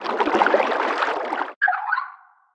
Sfx_creature_penguin_idlesea_B_03.ogg